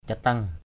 /ca-tʌŋ/